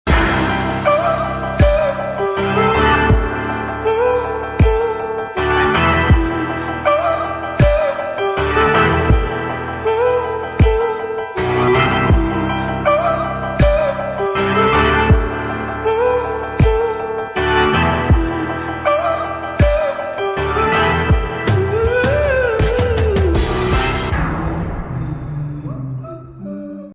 soulful vocals
ethereal production